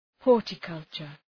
Προφορά
{‘hɔ:rtə,kʌltʃər}